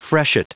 Prononciation du mot freshet en anglais (fichier audio)
Prononciation du mot : freshet